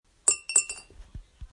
Index of /html/coin_sounds/